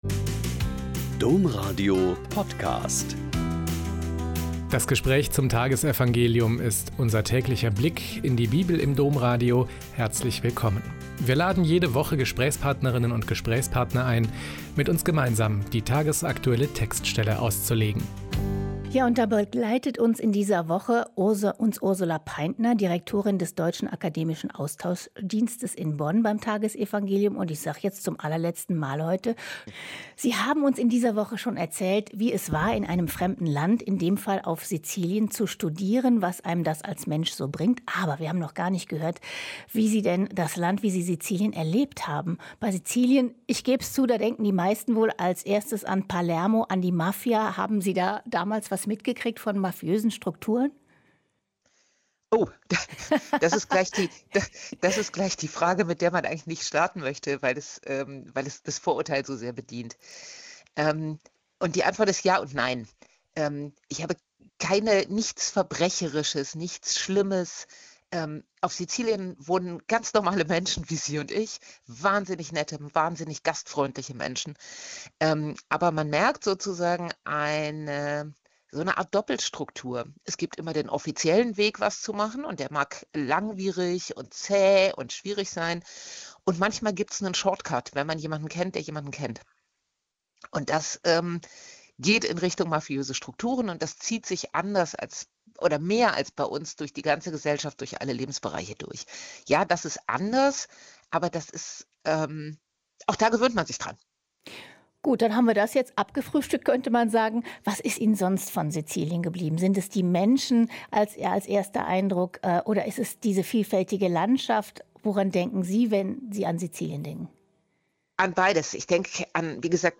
Lk 6,43-49 - Gespräch